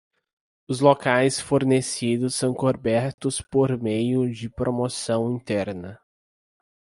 Pronounced as (IPA) /loˈkajs/